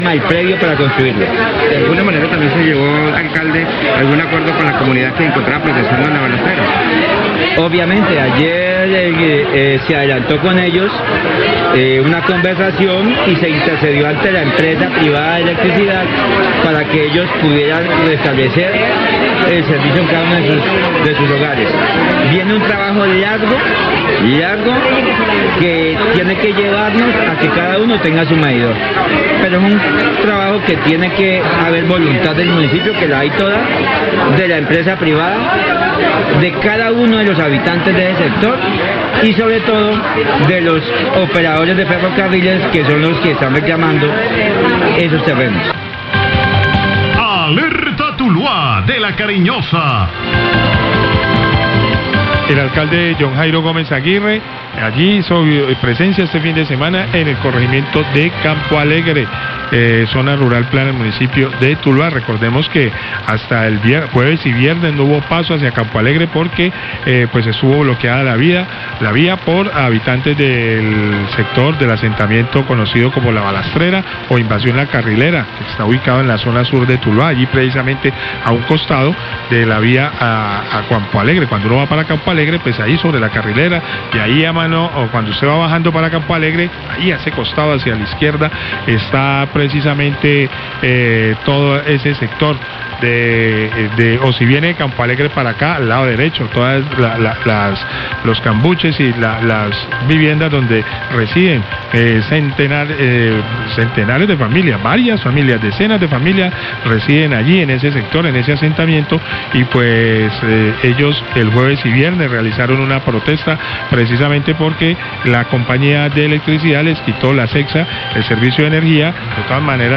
Radio
Declaraciones del alcalde de Tuluá sobre el acuerdo al que se pudo llegar con la comunidad de La Balastrera, que durante el jueves y viernes cerró la vía al corregimiento de Campo Alegre para reclamar el servicio de energía que les había sido suspendido. Según el alcalde la comunidad necesita atención urgente y el proceso que se debe adelantar en el sector es largo y complejo, sin embargo lograron acuerdos que permitieron levantar los bloqueos.